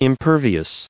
1775_impervious.ogg